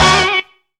HAMMER ON.wav